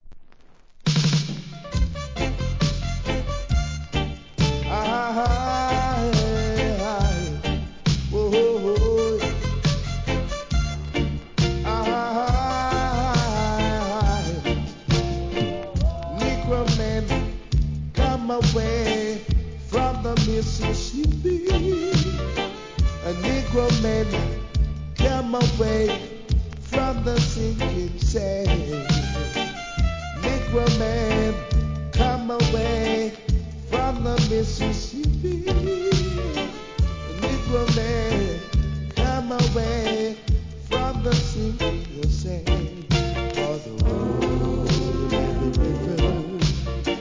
REGGAE
ゆったりと刻むRHYTHMにトランペットが心地よいトラック! コーラスも盛り上げます!